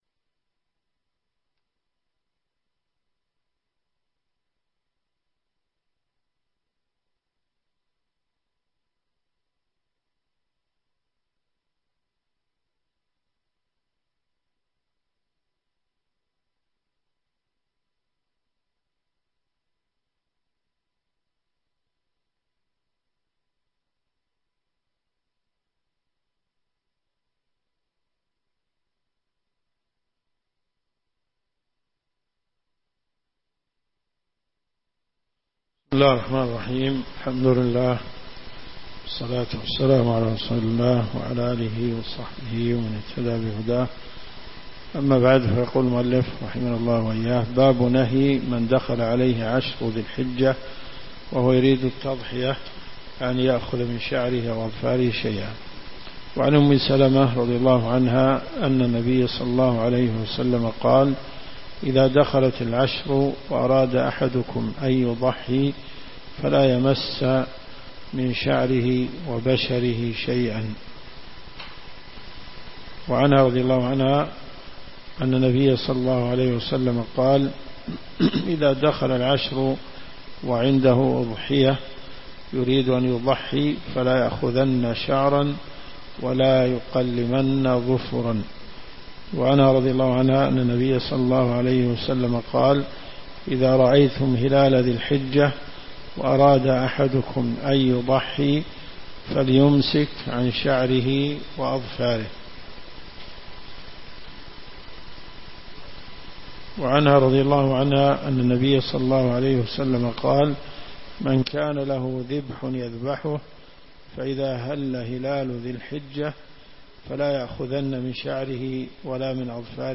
الرئيسية الكتب المسموعة [ قسم الحديث ] > صحيح مسلم .